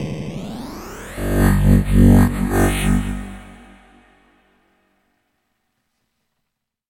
盐酸
描述：这是一个声音样本的“盐酸”通过audioterm并发送到waldorf blofeld作为波表。 blofeld向上推，然后扫描波表（通过模块轮，这很有趣），播放你在这里听到的内容，加上一些外置动词。听起来像一个声码器，但事实并非如此。与传统采样器相反，音调与采样的回放速率/长度无关。
Tag: 电子 audioterm 合成器 声乐 华尔 波浪 盐酸 扫描 blofeld 有趣与 - 化学 波表